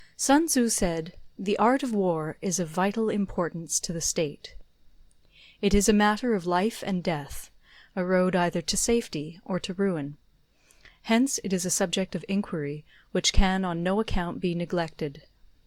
voice.opus